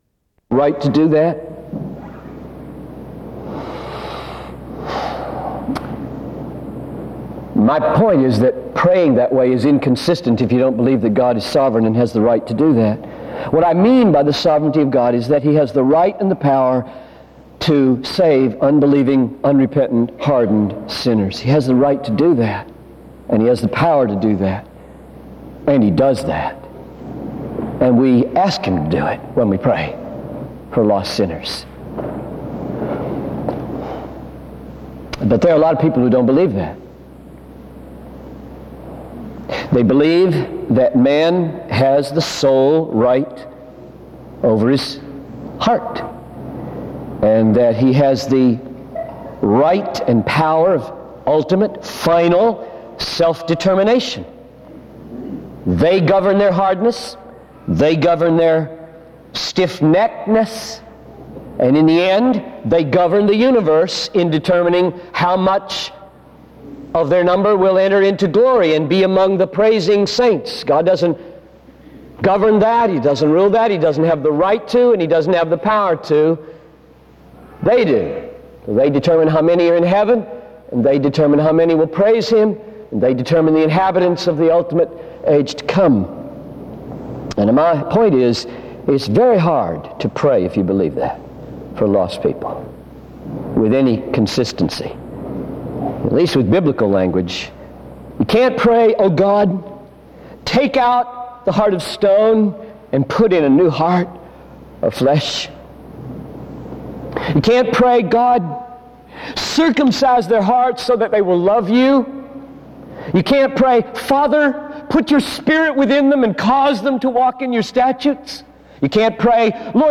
Home SEBTS Carver-Barnes Lecture...